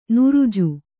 ♪ nuruju